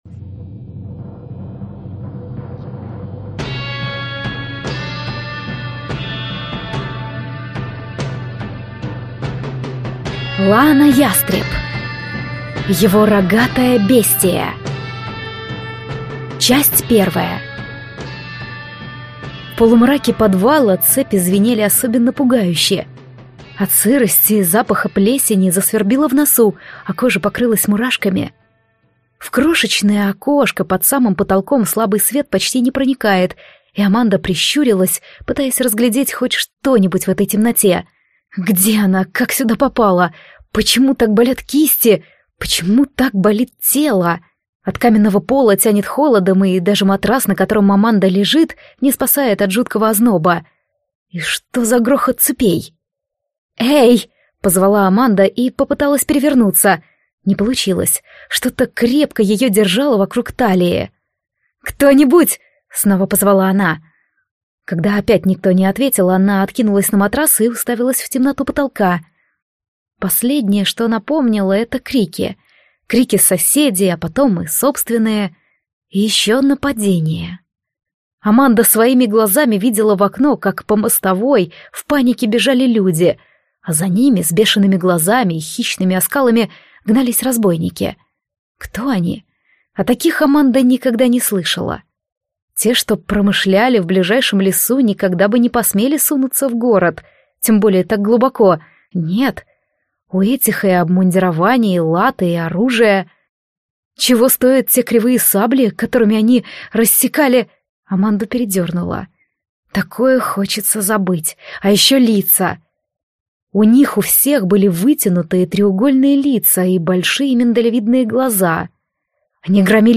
Аудиокнига Его рогатая бестия | Библиотека аудиокниг
Прослушать и бесплатно скачать фрагмент аудиокниги